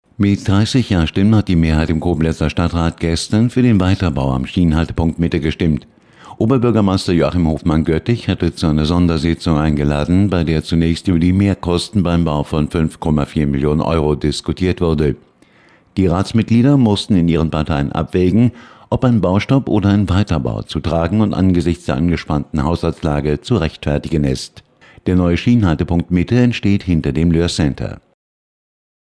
Antenne Koblenz 98,0 am 18.01.2011, Nachrichten 8.30 Uhr  (Dauer 00:28 Minuten)